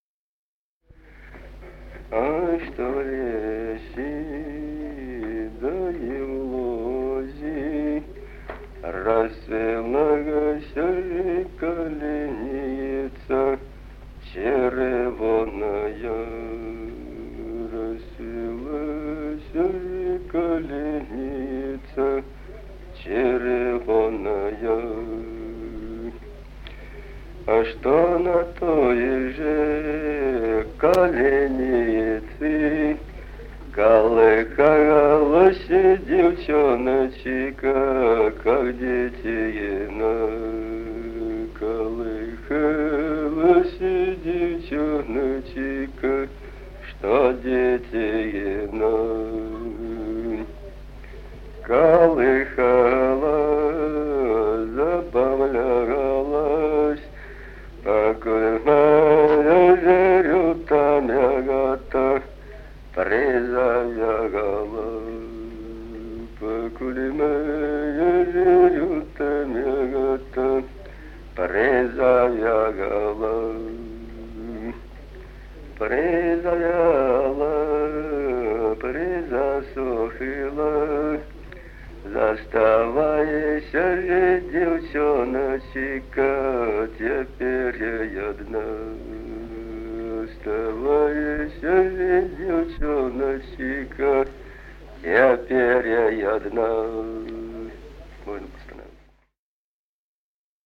Песни села Остроглядово Ай, что в лесе да и в лузе.
Песни села Остроглядово в записях 1950-х годов